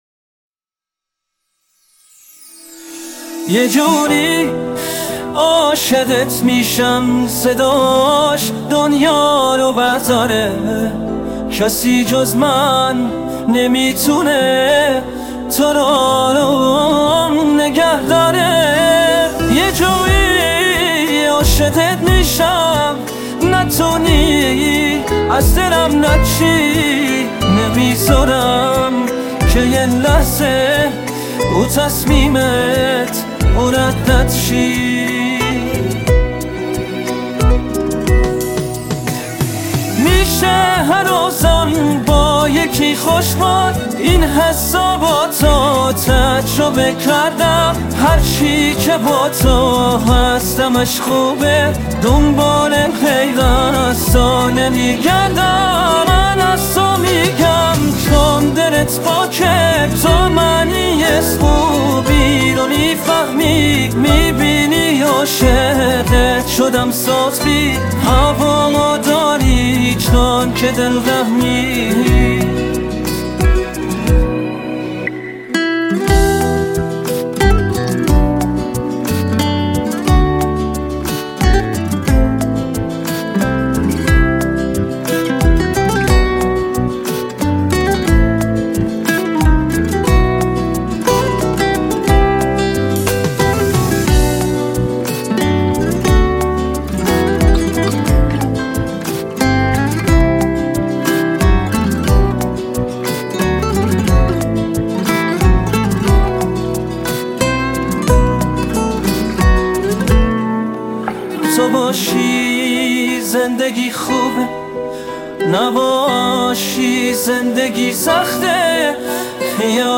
ژانر: پاپ
آهنگ هوش مصنوعی